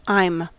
Uttal
Uttal US Okänd accent: IPA : /aɪm/ unstressed: IPA : /əm/ IPA : /ʌm/ Ordet hittades på dessa språk: engelska Ingen översättning hittades i den valda målspråket.